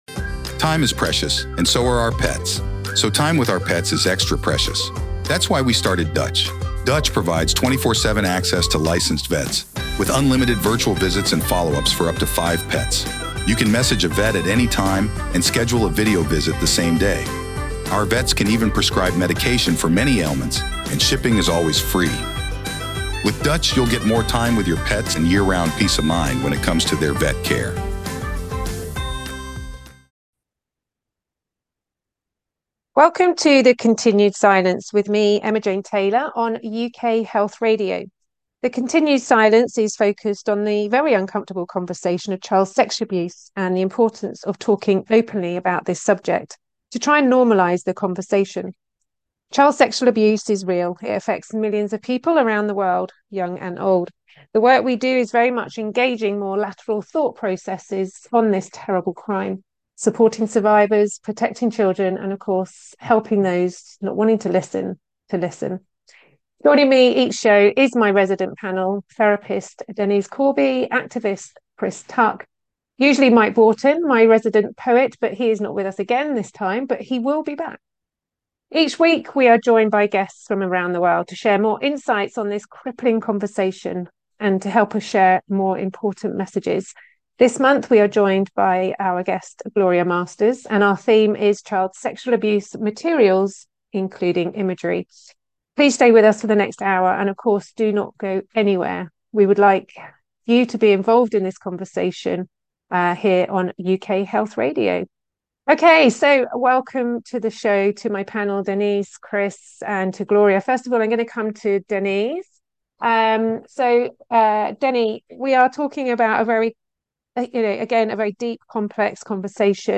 …the continued Silence is a panel chat show